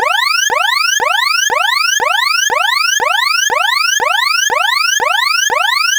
alert-alaram.wav